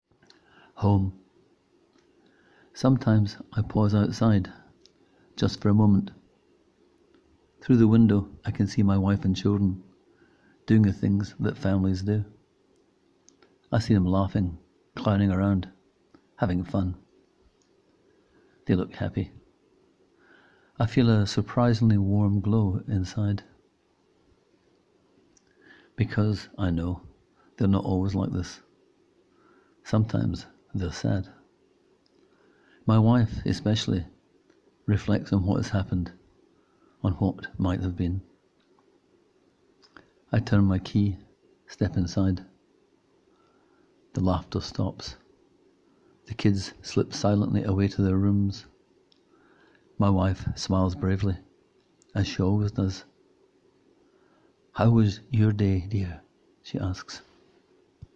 Click here to hear the author read this 1-minute story: